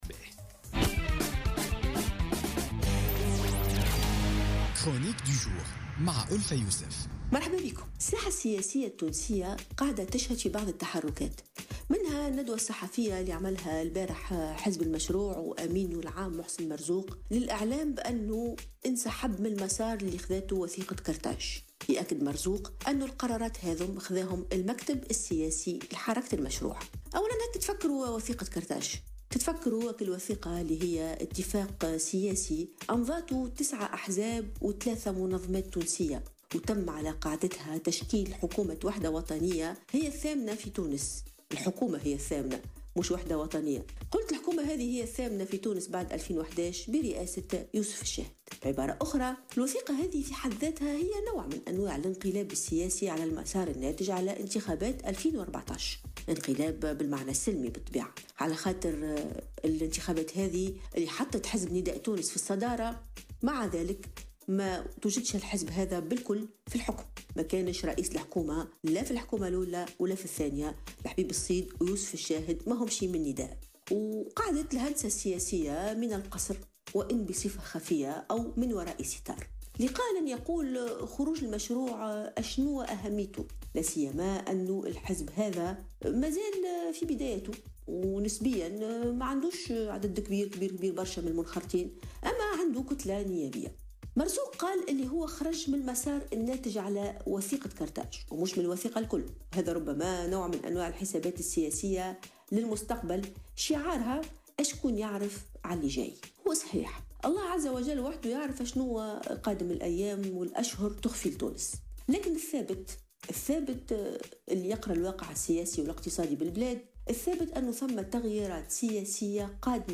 أكد أحد المواطنين اليوم الجمعة في برنامج بولتيكا تعرضه لمحاولة ابتزاز وتحيل من شخص قصد منزله وأدى أنه يعمل بإدارة المحاسبات وأنه يقوم بحملة لإستخلاص التبرعات لفائدة جمعية تابعة للإدارة.